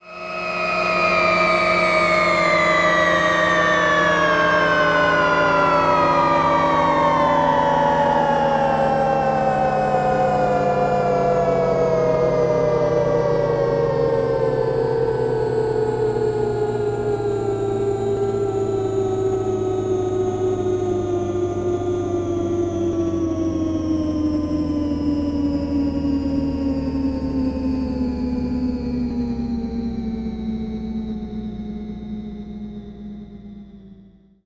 Kill A Robot; Very Slow Machine Motor Winding Down.